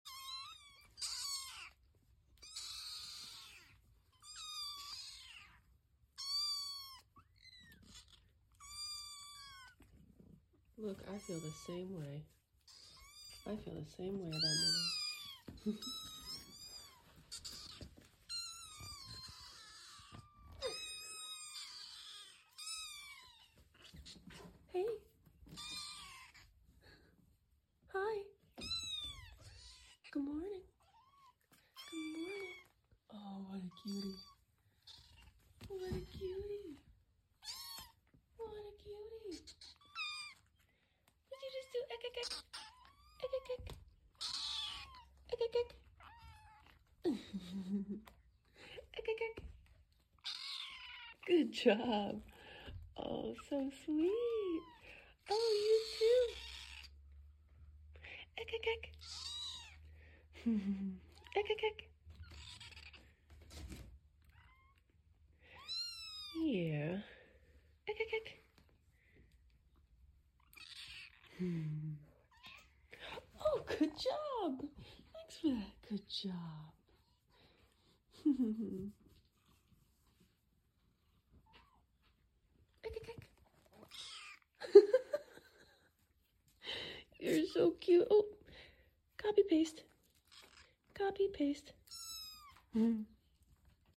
New sound unlocked!!! Did we just figure out how to ekekekk?!?!